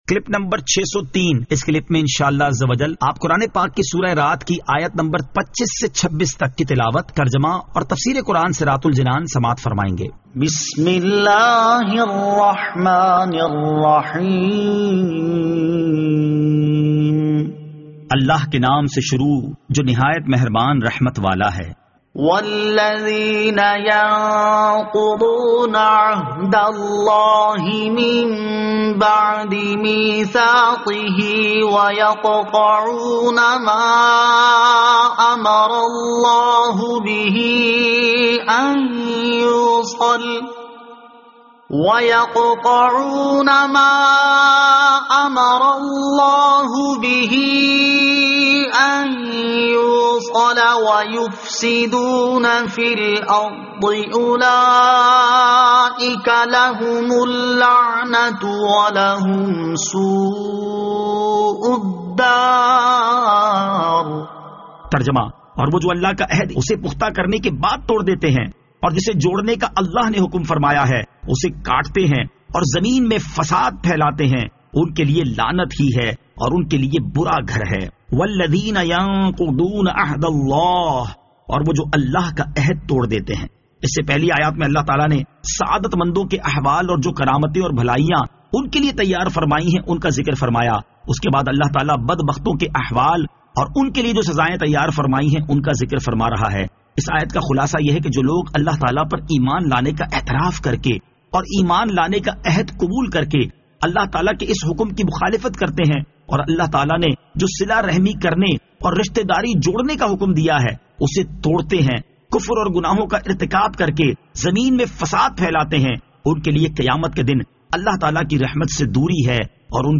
Surah Ar-Rad Ayat 25 To 26 Tilawat , Tarjama , Tafseer
2021 MP3 MP4 MP4 Share سُوَّرۃُ الرَّعٗد آیت 25 تا 26 تلاوت ، ترجمہ ، تفسیر ۔